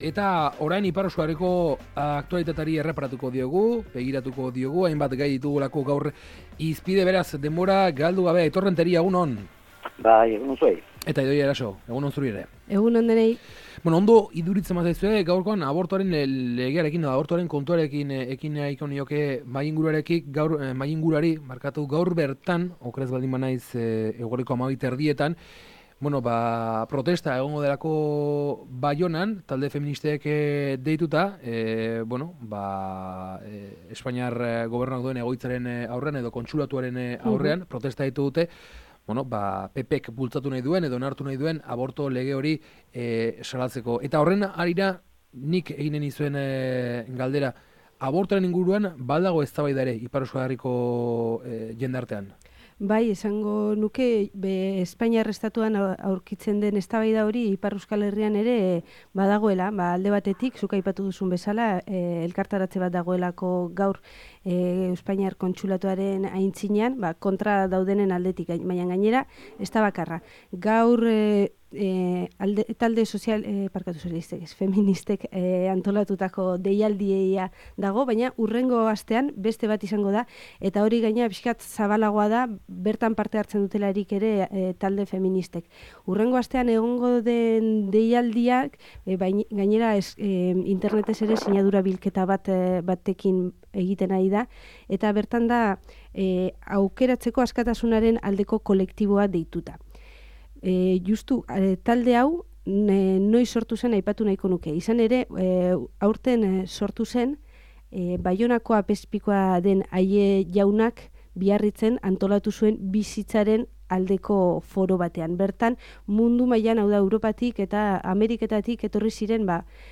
Ipar Euskal Herriko aktualitatea mahai-inguruan / Soinuola